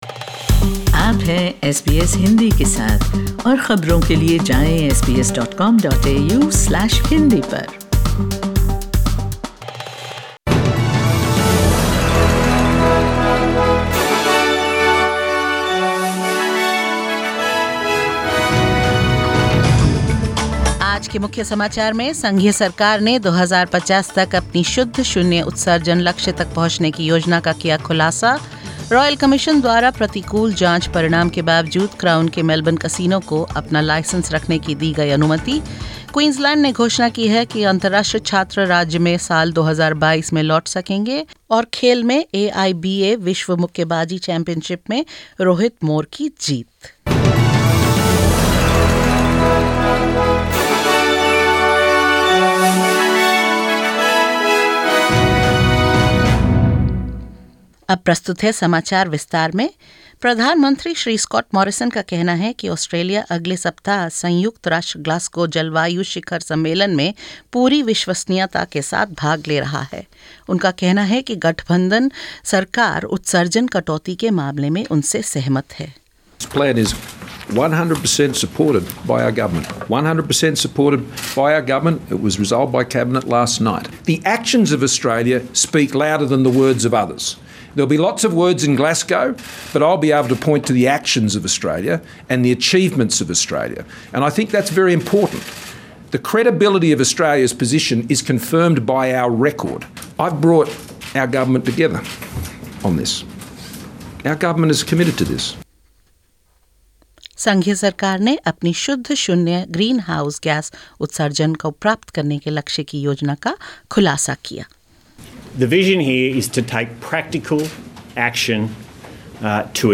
In this latest SBS Hindi News bulletin of Australia and India: Prime Minister Scott Morrison unveils Australia's plan to achieve net zero emissions by 2050; Crown to keep Melbourne casino license following a series of enquiries by a royal commission; Queensland plans to reopen borders to fully vaccinated international students by 2022 and more.